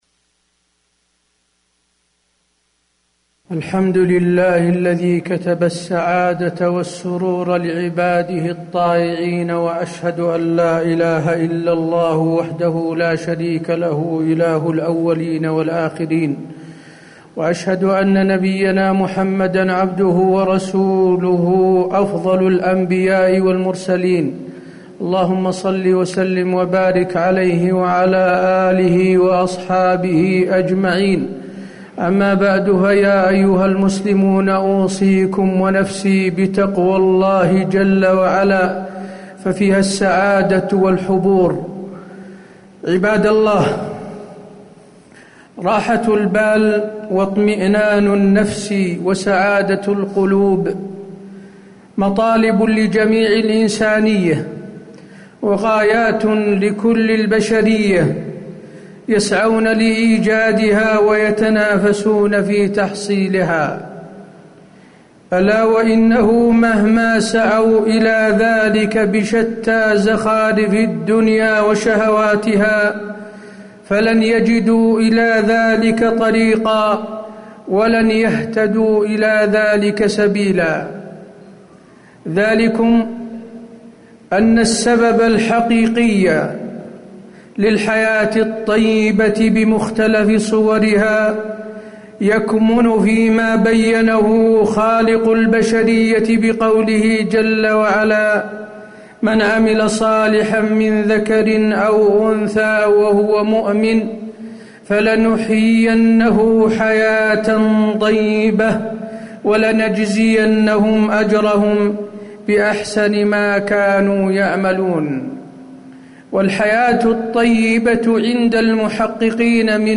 تاريخ النشر ٢٧ شوال ١٤٣٨ هـ المكان: المسجد النبوي الشيخ: فضيلة الشيخ د. حسين بن عبدالعزيز آل الشيخ فضيلة الشيخ د. حسين بن عبدالعزيز آل الشيخ كيف نعيش حياة طيبة